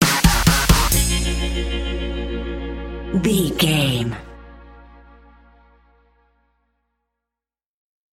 Aeolian/Minor
B♭
drums
synthesiser
electric guitar
hard rock
bass